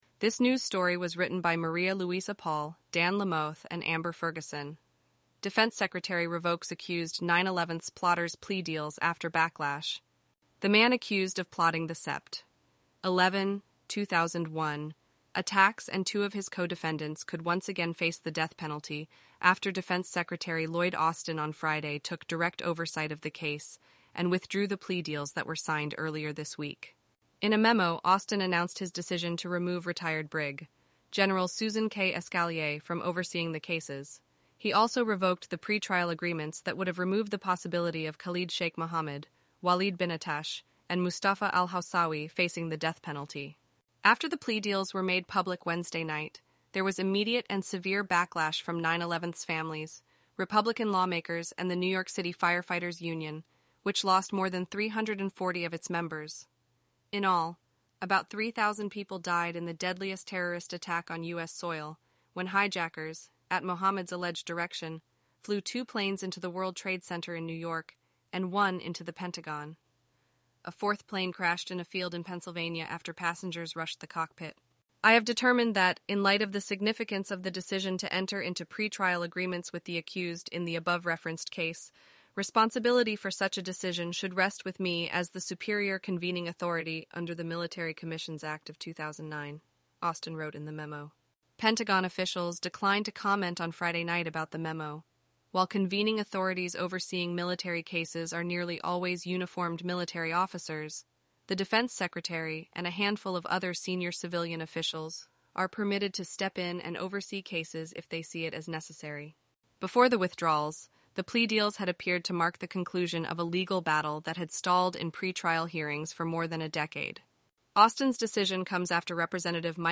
eleven-labs_en-US_Rachel_standard_audio.mp3